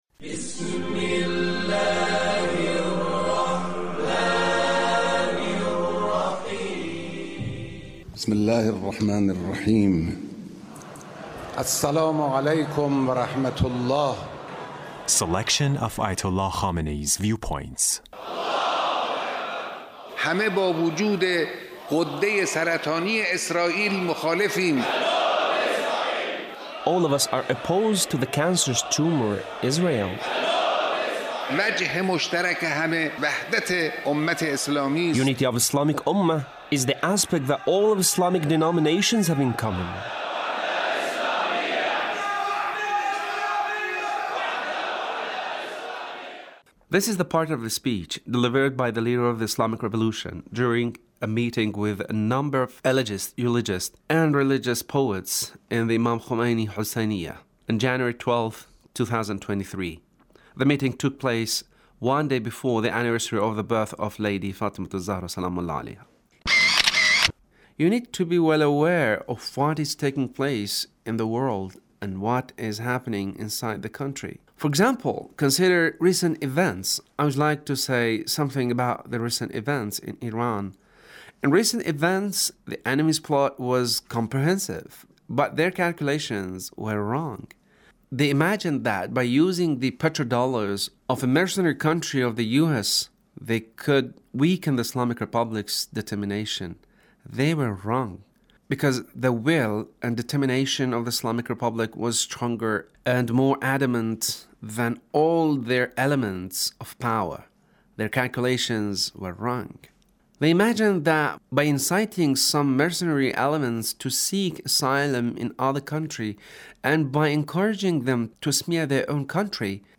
Leader's Speech meeting with Eulogists